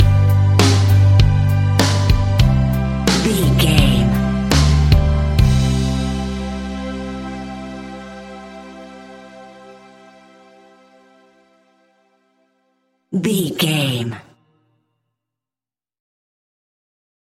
Ionian/Major
Slow
calm
melancholic
happy
smooth
soft
uplifting
electric guitar
bass guitar
drums
indie pop
organ